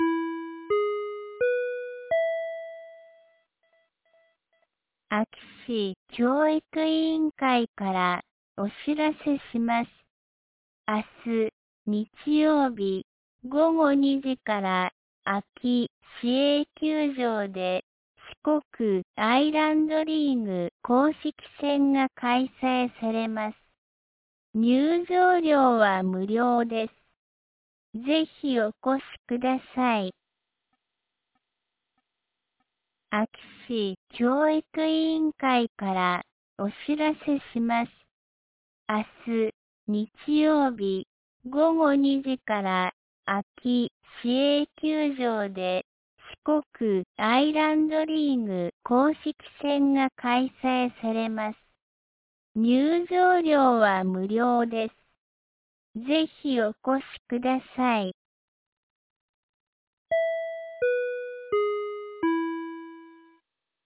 2025年09月06日 17時11分に、安芸市より全地区へ放送がありました。